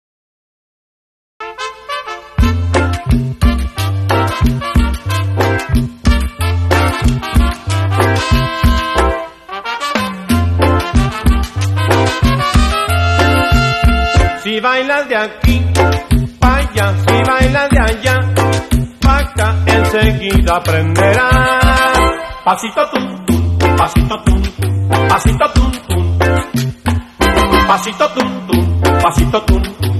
Mexican music instead of car advice